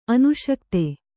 ♪ anuśakte